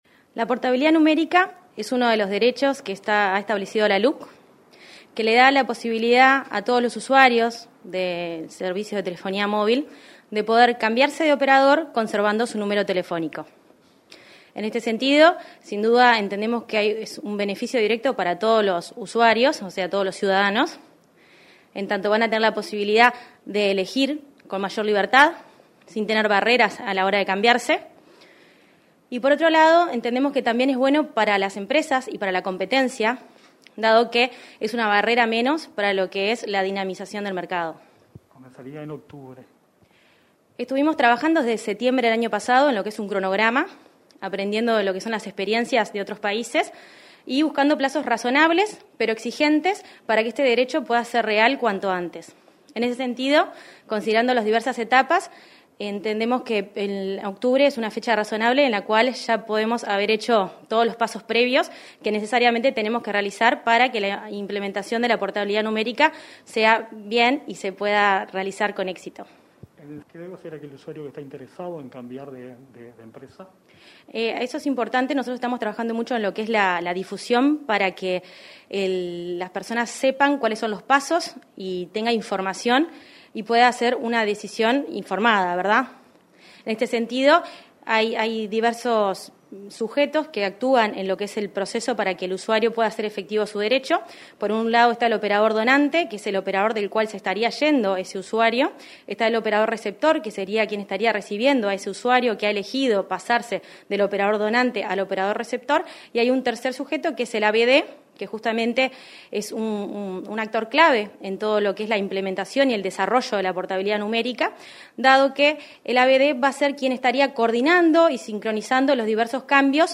Entrevista a la presidenta de la Unidad Reguladora de Servicios de Comunicaciones, Mercedes Aramendía